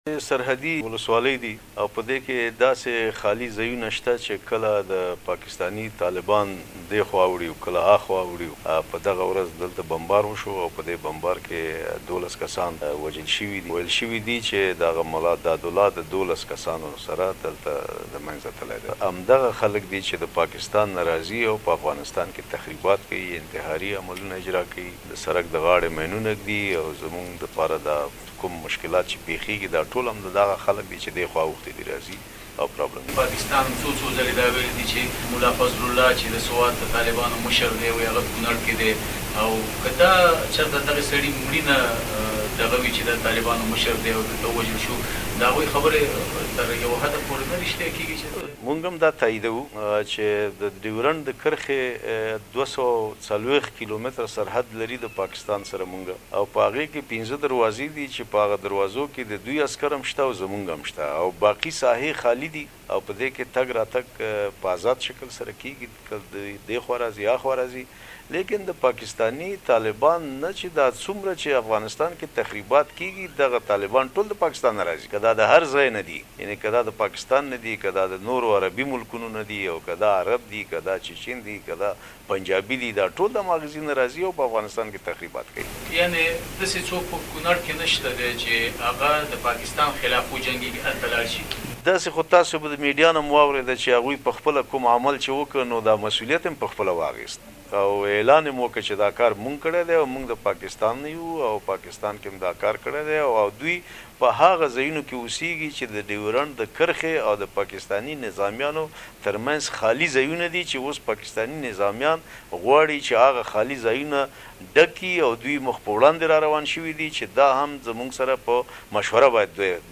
د کونړ له والي سيد فضل الله وحيدي سره مرکه